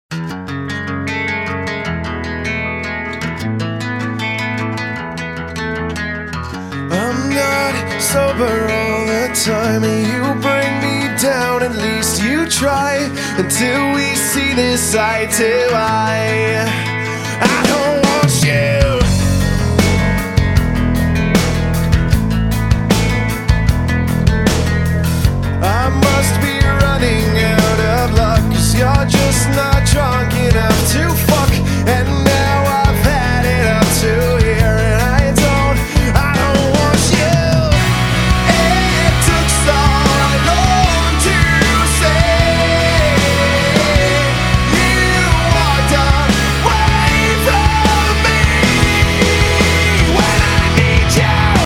• Качество: 320, Stereo
гитара
рок
проигрыш на гитаре